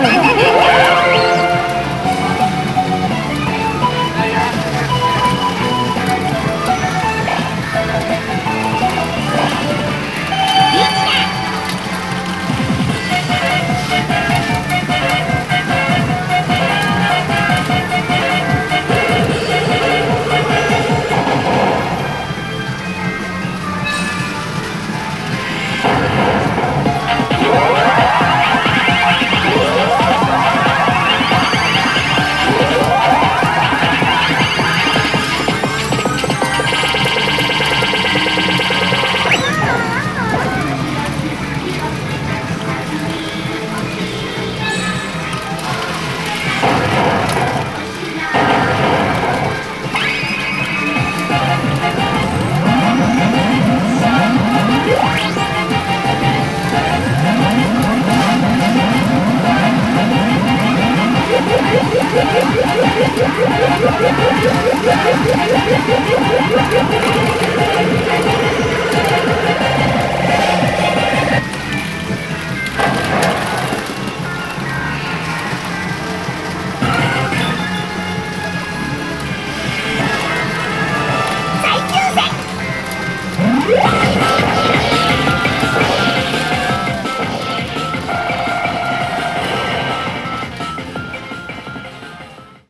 pachinko5.mp3